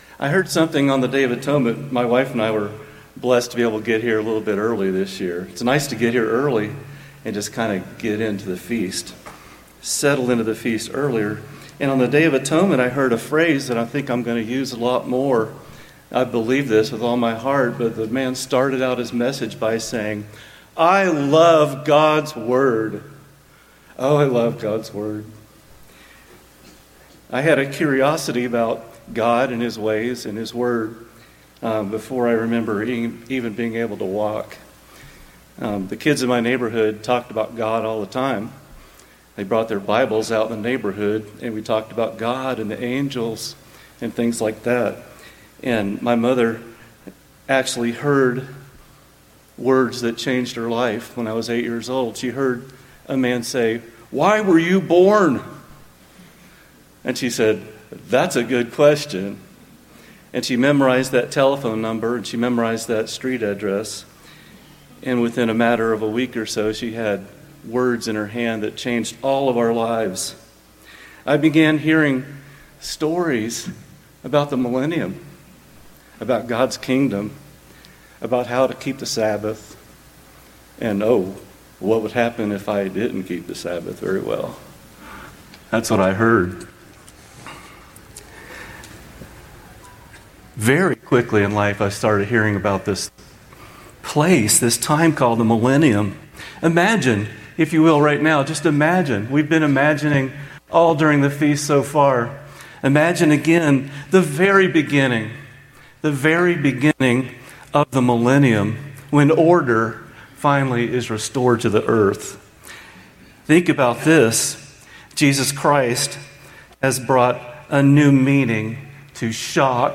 Sermons
Given in Rapid City, South Dakota